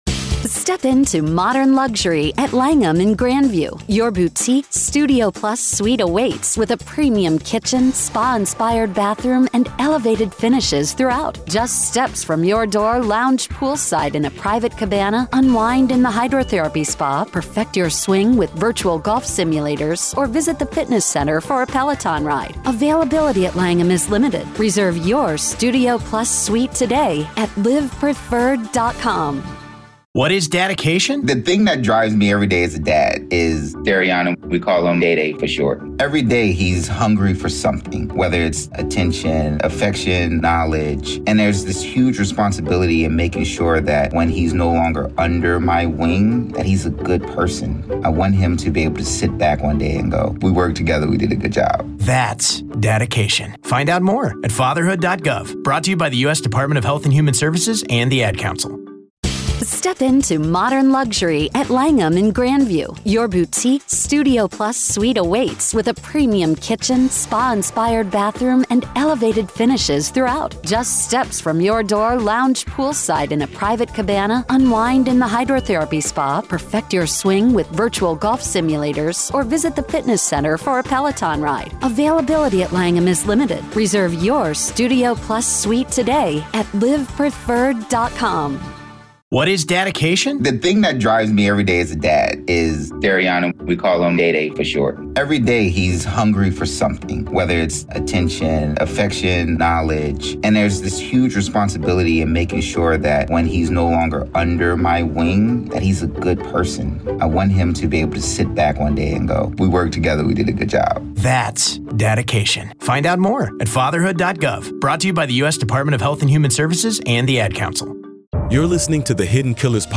The conversation touches on the challenges of proving long-standing allegations and the shifting societal stance on survivors coming forward. Main Points: The FBI raid on Diddy's home sparked debate over the appropriateness of the force used.